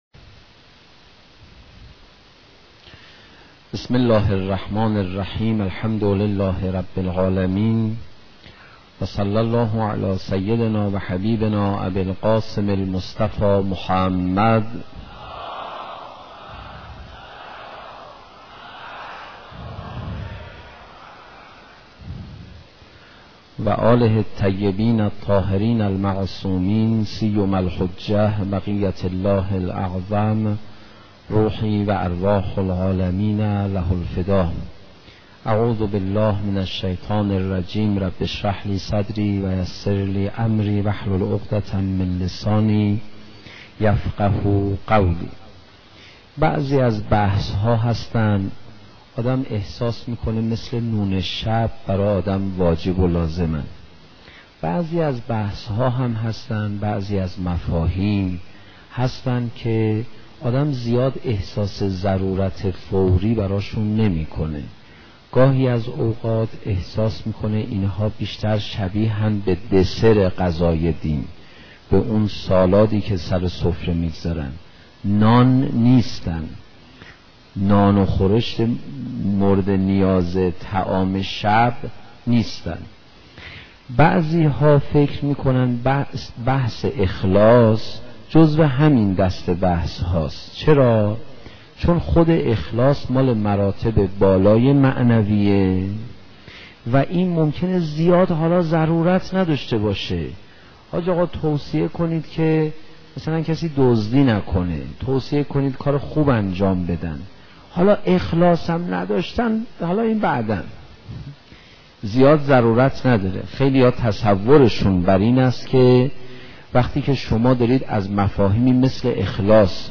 سخنرانی حاج آقای پناهیان با موضوع اخلاص